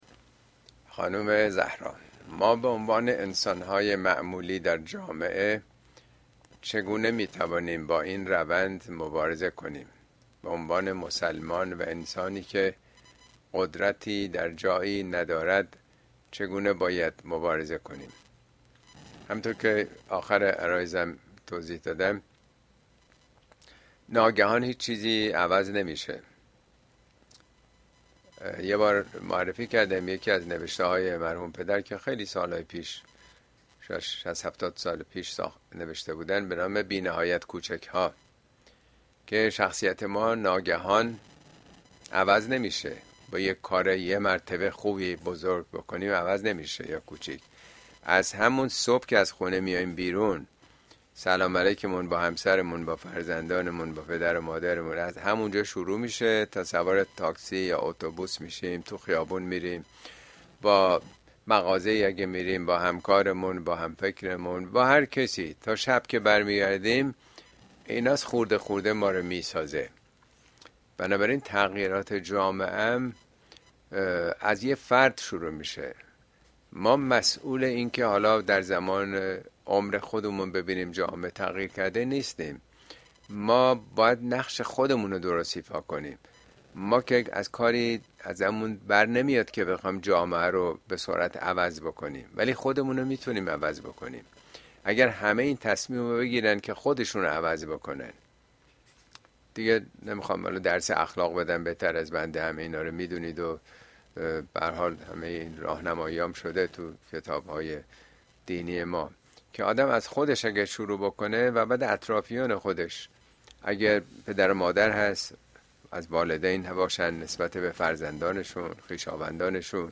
` موضوعات اجتماعى اسلامى سلطه سرمایه بر سياست اين سخنرانى به تاريخ ۹ آپریل ۲۰۲۵ در كلاس آنلاين پخش شده است توصيه ميشود براىاستماع سخنرانى از گزينه STREAM استفاده كنيد.